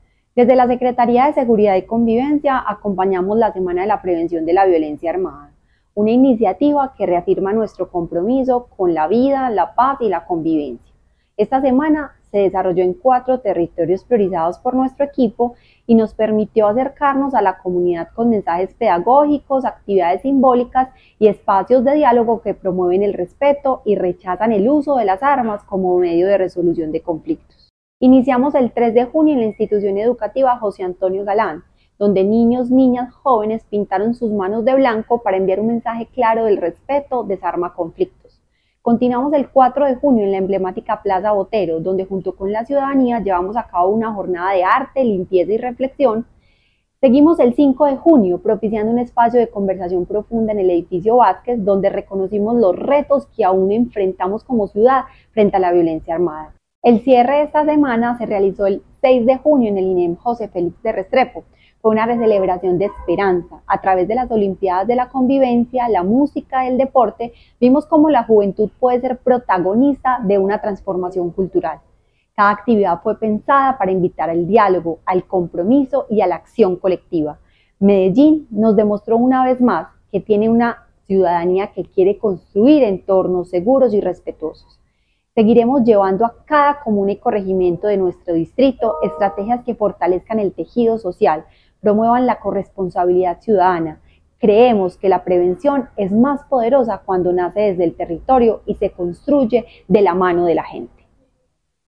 Declaraciones subsecretaria de Gobierno Local, Laura Hernández
Declaraciones-subsecretaria-de-Gobierno-Local-Laura-Hernandez.mp3